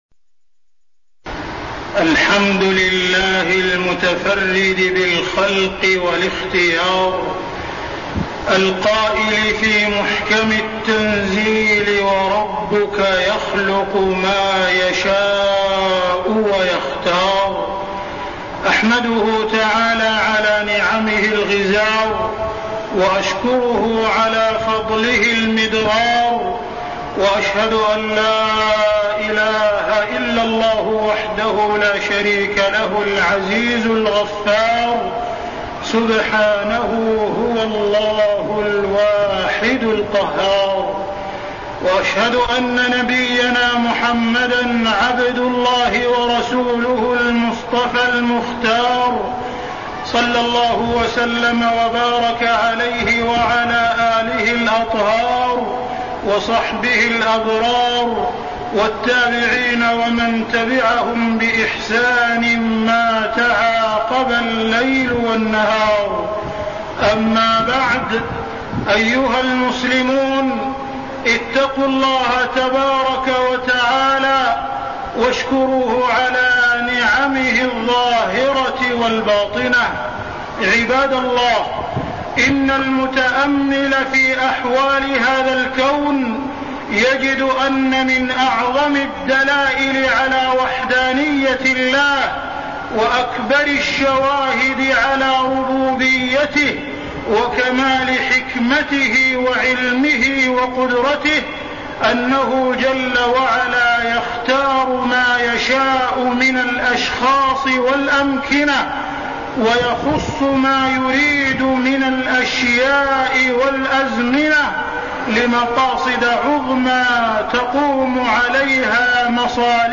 تاريخ النشر ١١ ذو القعدة ١٤١٤ هـ المكان: المسجد الحرام الشيخ: معالي الشيخ أ.د. عبدالرحمن بن عبدالعزيز السديس معالي الشيخ أ.د. عبدالرحمن بن عبدالعزيز السديس فضائل مكة